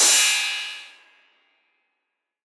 808CY_2_TapeSat_ST.wav